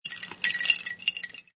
sfx_ice_moving03.wav